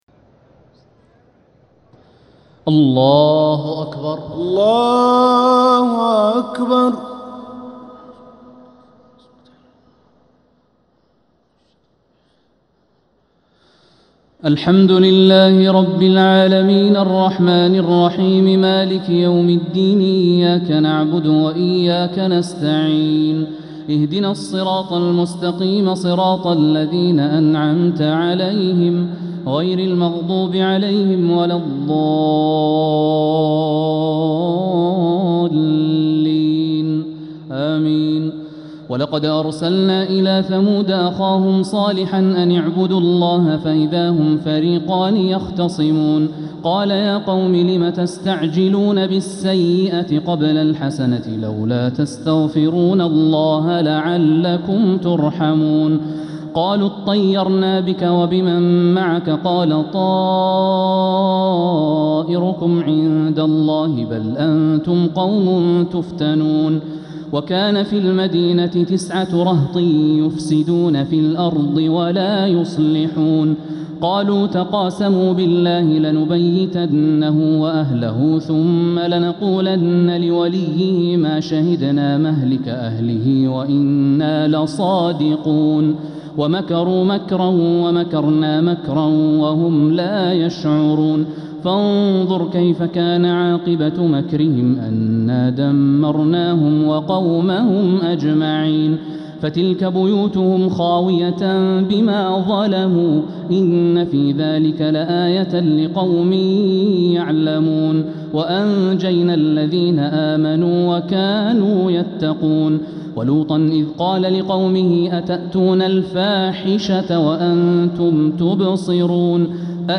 تراويح ليلة 23 رمضان 1447هـ من سورتي النمل (45-93) و القصص (1-13) | taraweeh 23rd night Ramadan 1447H Surah An-Naml and Al-Qasas > تراويح الحرم المكي عام 1447 🕋 > التراويح - تلاوات الحرمين